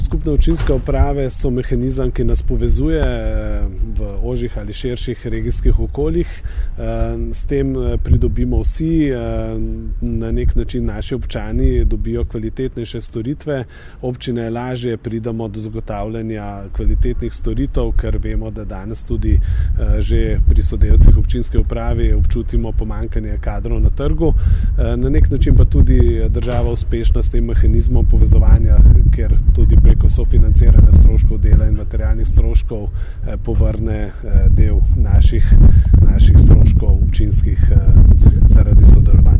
Novomeški župan Gregor Macedoni o skupnih občinskih upravah občin